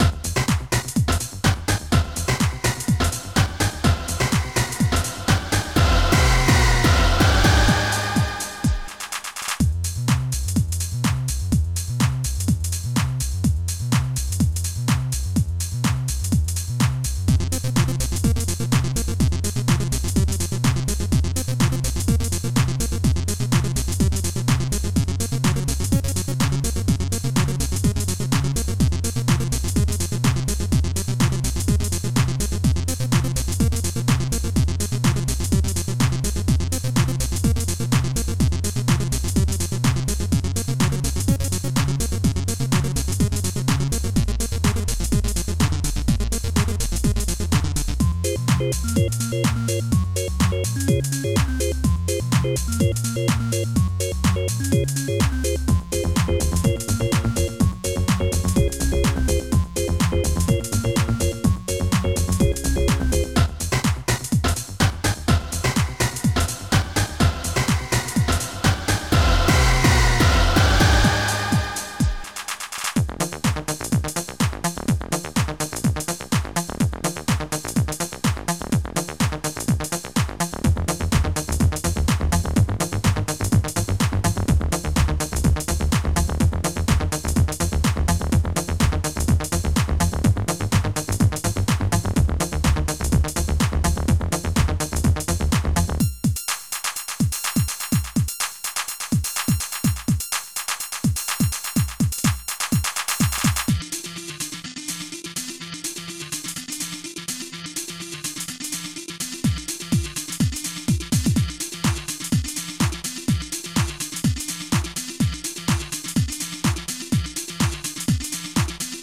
jbid.choir
sinus
square
basicrythm.bd+clh
basicrythm.clp+clh